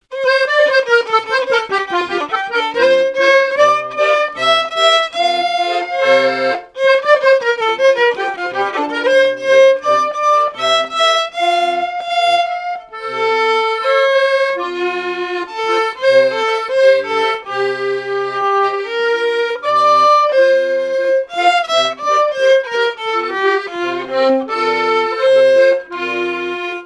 Marche pour noce
marches de noces jouées aux Gueurnivelles
Pièce musicale inédite